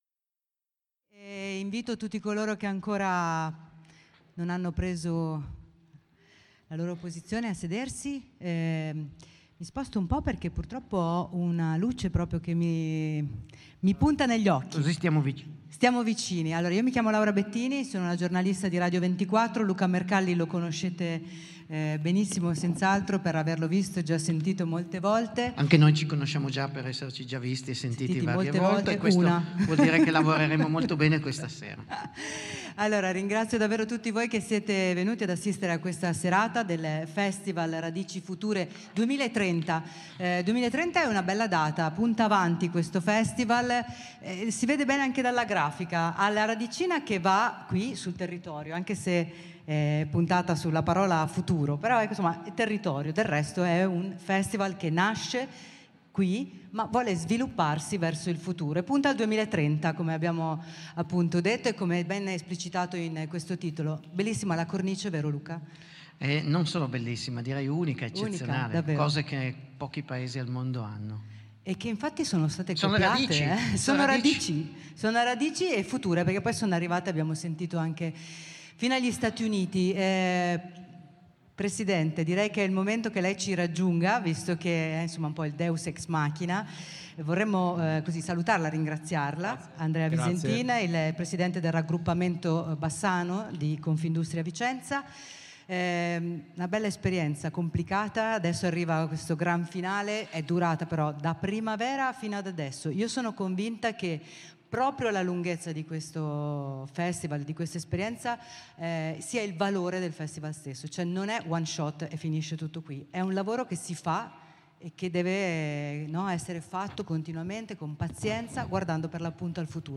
Edizione 2021 L'edizione numero uno del festival, ma soprattutto l’inizio di un viaggio per gettare le basi di un futuro, non troppo lontano, che sia sostenibile.